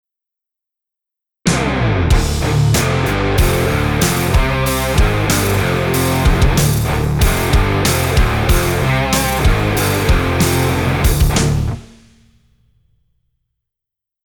a mix of crunchy distortion and deep, groove-heavy rhythms